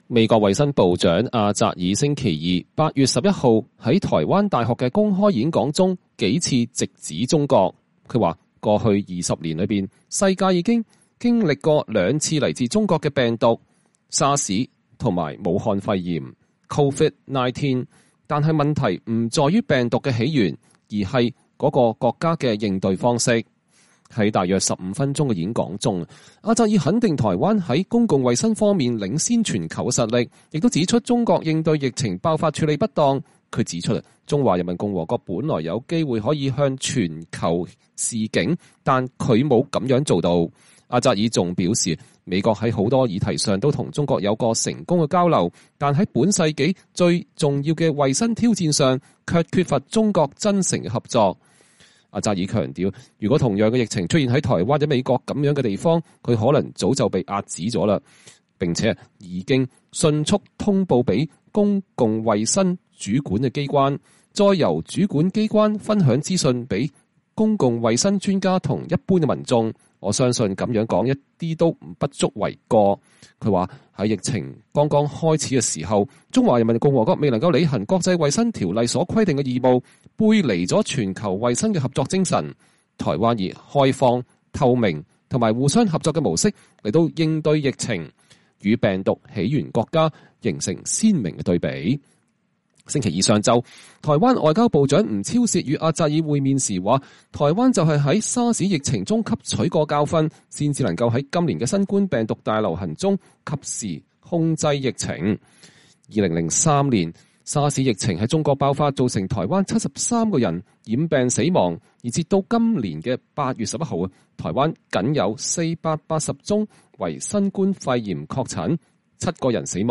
美國衛生部長阿扎爾在台北的台灣大學發表演講。（2020年8月11日）